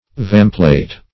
vamplate - definition of vamplate - synonyms, pronunciation, spelling from Free Dictionary
Search Result for " vamplate" : The Collaborative International Dictionary of English v.0.48: Vamplate \Vam"plate`\, n. [F. avant before, fore + E. plate.]